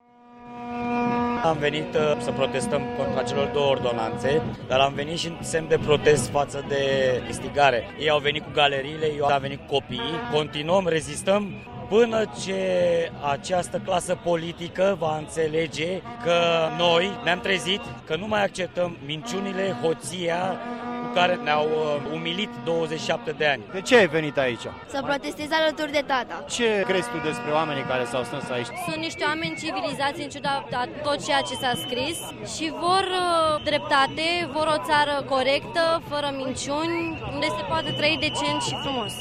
La protest, care a fost unul lipsit de incidente, au participat tineri sau bătrâni, dotaţi cu steaguri, pancarte, vuvuzele şi tobe.
a stat de vorbă cu câţiva dintre participanţi: